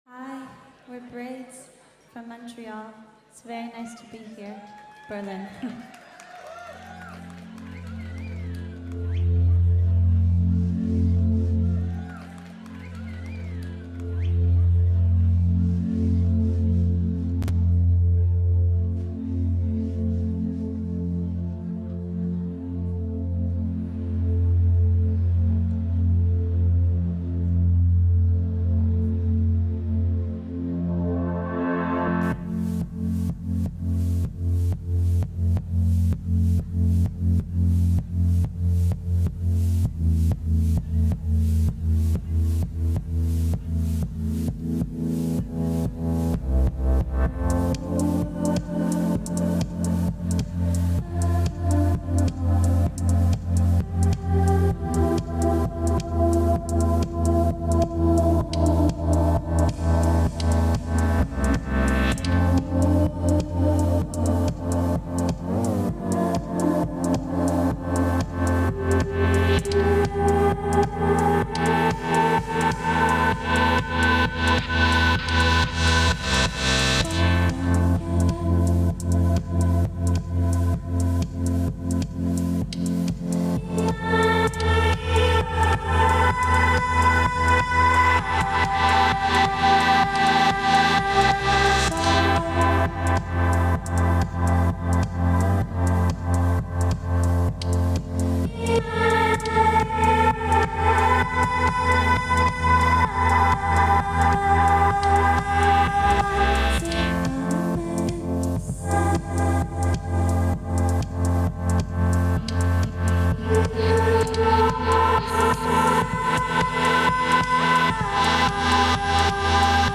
Live at Lido, Berlin
Canadian Shoegaze comes to Berlin.